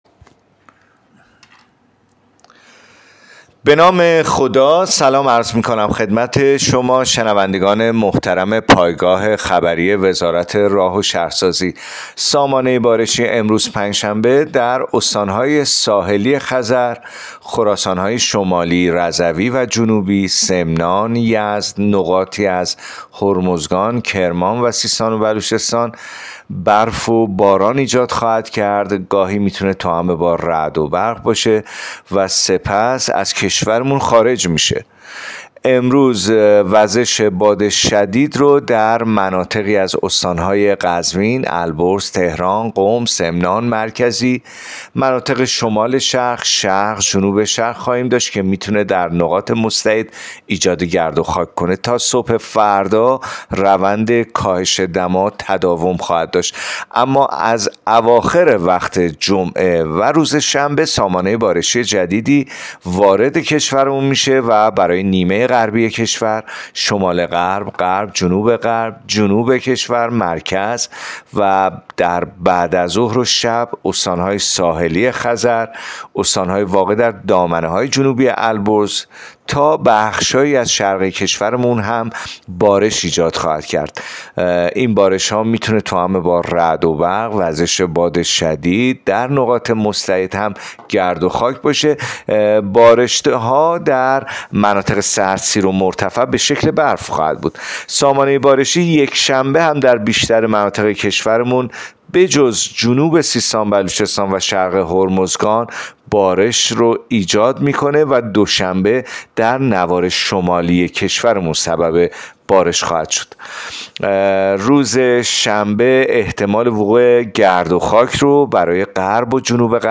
گزارش رادیو اینترنتی از آخرین وضعیت آب و هوای شانزدهم بهمن؛